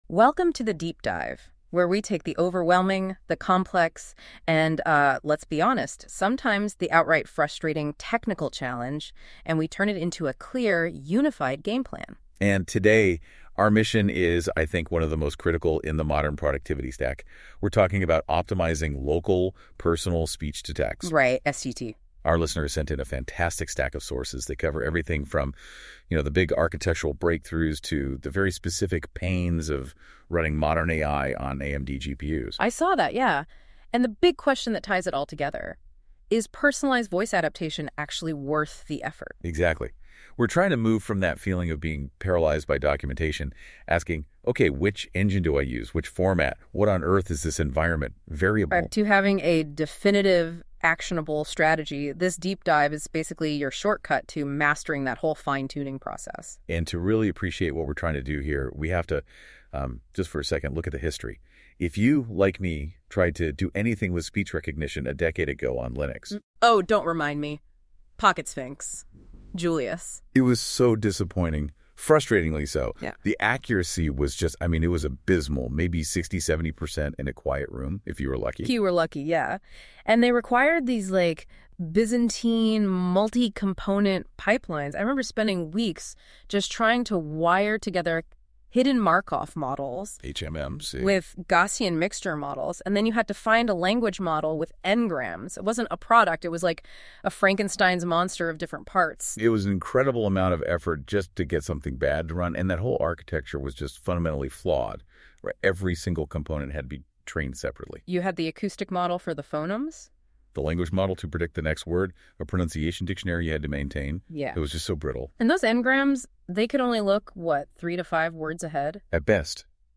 AI-Generated Content: This podcast is created using AI personas.
TTS Engine chatterbox-tts
Hosts Herman and Corn are AI personalities.